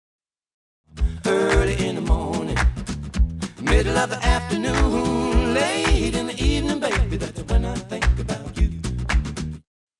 music.wav